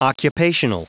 Prononciation du mot occupational en anglais (fichier audio)
Prononciation du mot : occupational